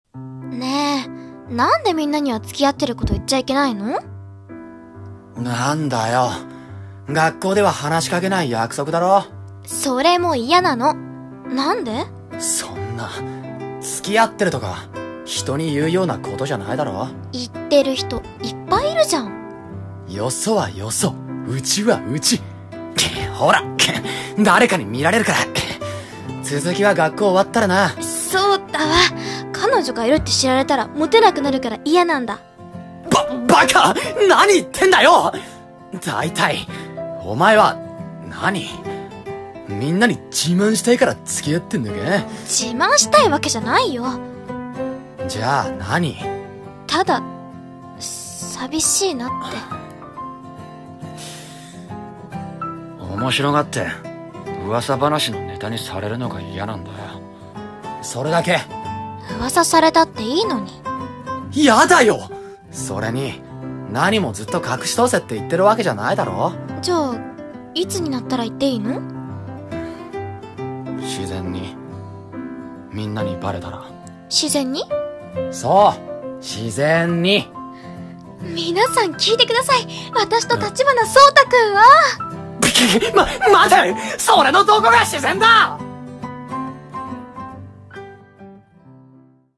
【声劇台本】みんなには内緒